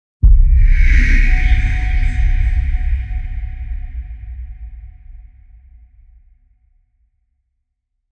Deep Bass Ghostly Sound Effect
Deep, powerful ghostly sound effect with hot, low-frequency bass tones and eerie textures.
Adds tension, atmosphere, and a chilling supernatural vibe to any scene.
Deep-bass-ghostly-sound-effect.mp3